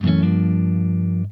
SLIDECHORD1.wav